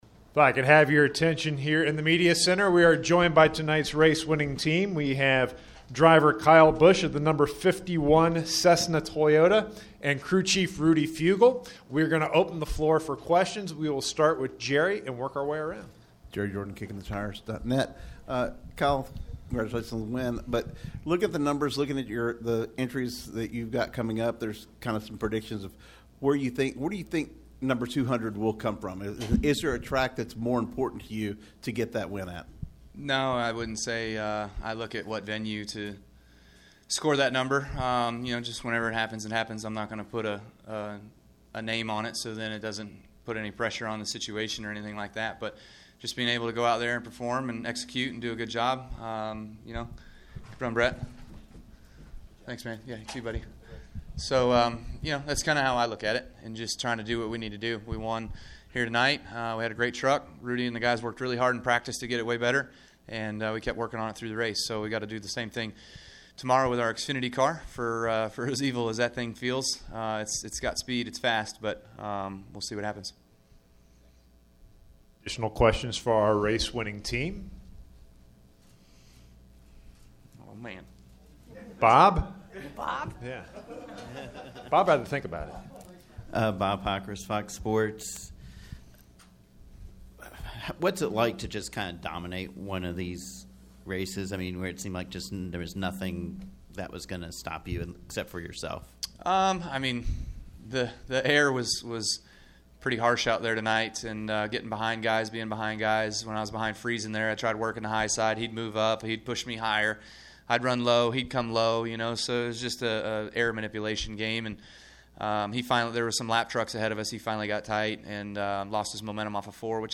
Media Center Interviews: